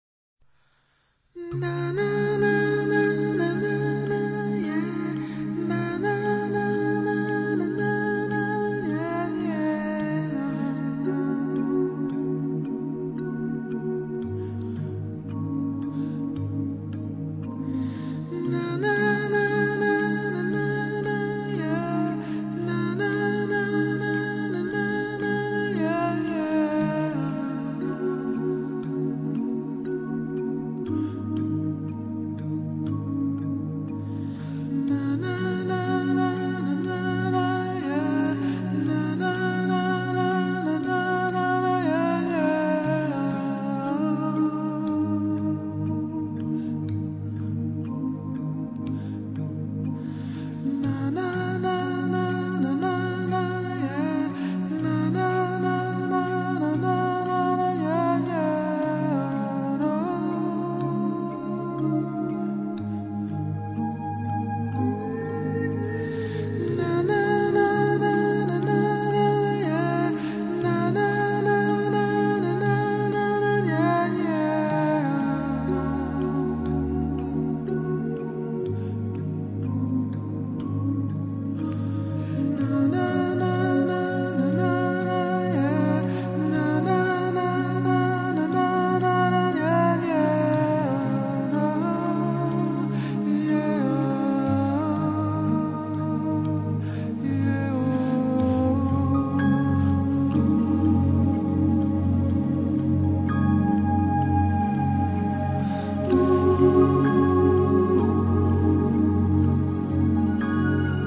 pegadiza e hipnótica melodía new age